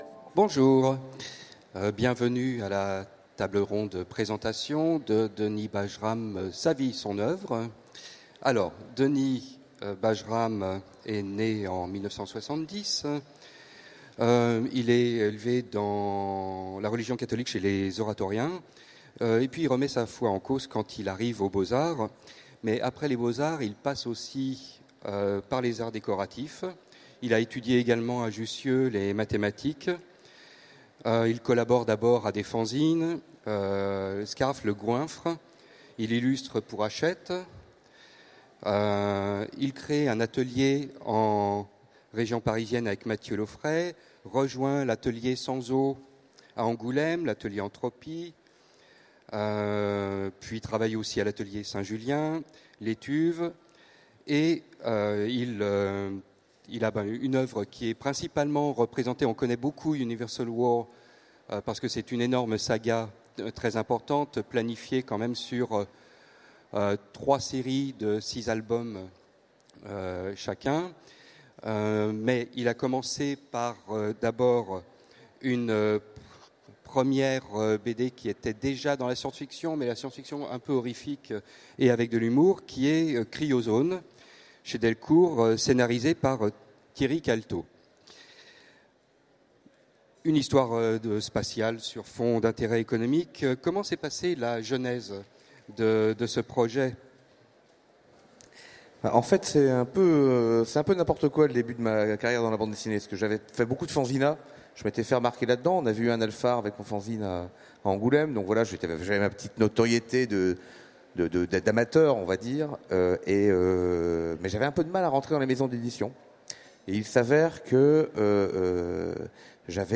- le 31/10/2017 Partager Commenter Utopiales 2016 : Rencontre avec Denis Bajram Télécharger le MP3 à lire aussi Denis Bajram Genres / Mots-clés Rencontre avec un auteur Conférence Partager cet article